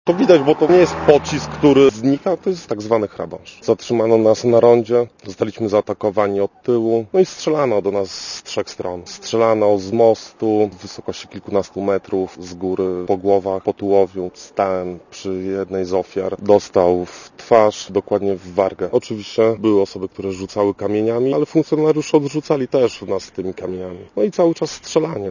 Posłuchaj relacji świadka
strzaly.mp3